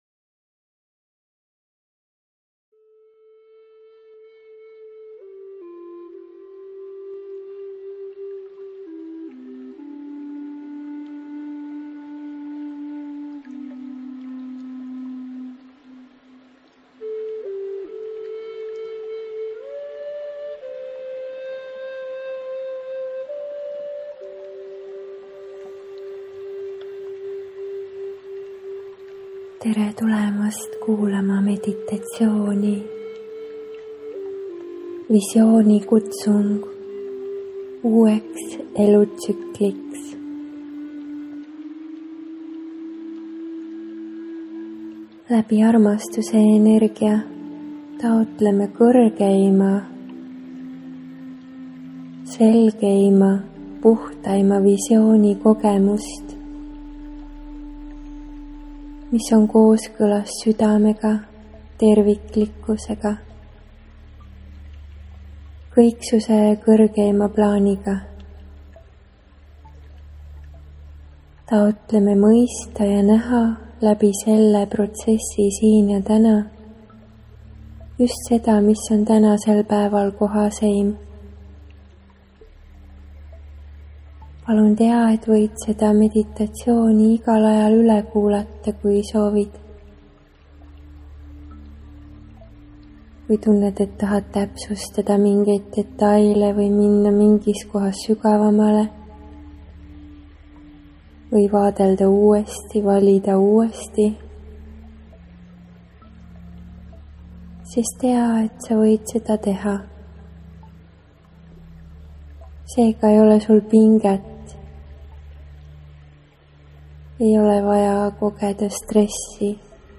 Võid seansi ajal minuga koos taotlusi lausuda või jääda ka lihtsalt rahusse, lõdvestuse ja lubada energiatel läbi enda voolata. Iga kord seda meditatsiooni kuulates saavad puhastatud Su meel, teadvus, keha ja süda.